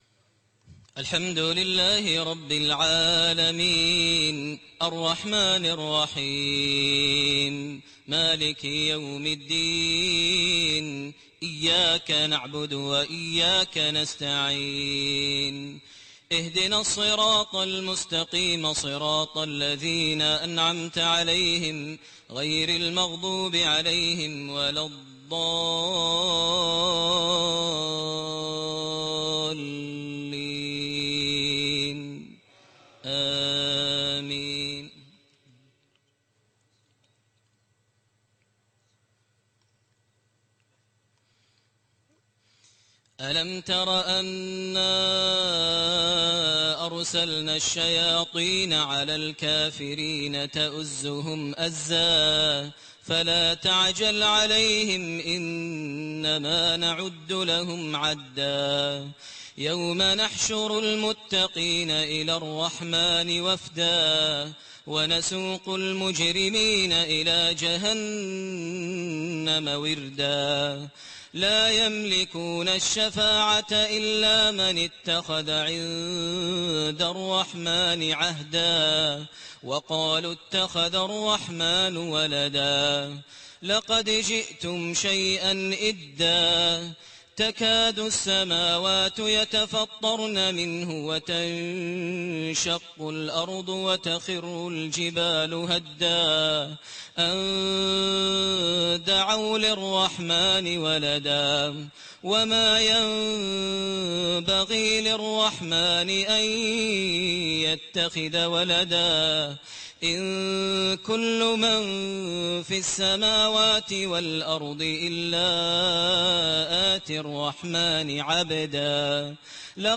Maghrib19/03/2008 prayer from Surat Maryam > 1429 H > Prayers - Maher Almuaiqly Recitations